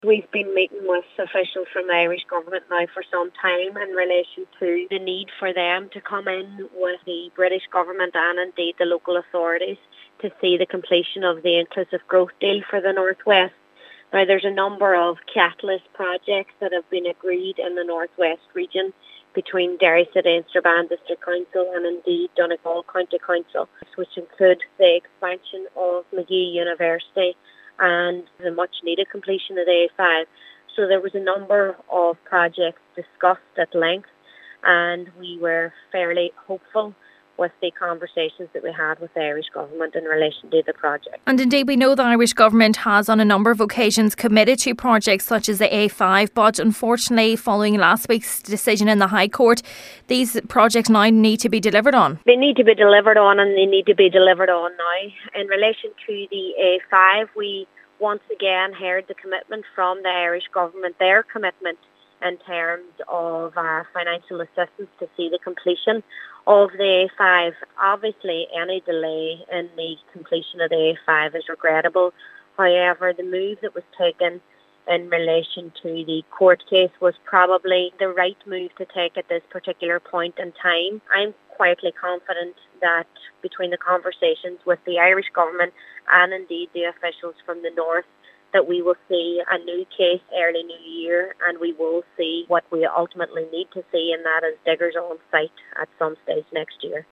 Foyle MP Elisha McCallion says she is hopeful that the Irish Government will come on board to support the delivery of these vital projects……….